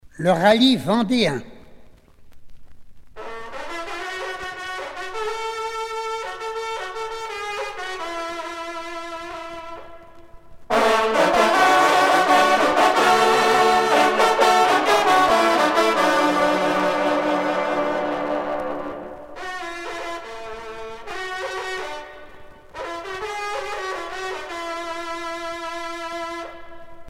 sonnerie vénerie - fanfare d'équipage